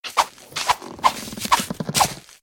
burrow.ogg